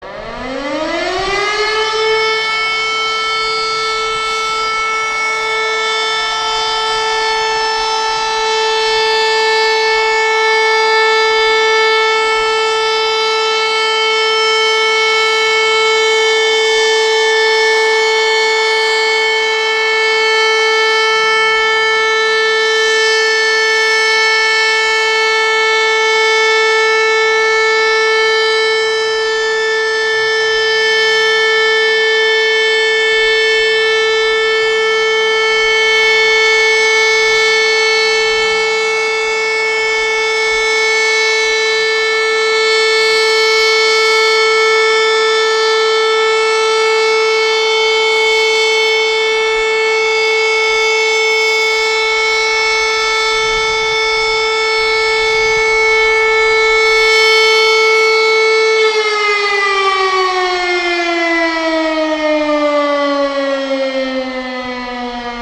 Syrena alarmowa - ciągły dźwięk - odwołanie alarmu
mp3,68,syrena-alarmowa-ciagly-dzwiek-odwolanie-alarmu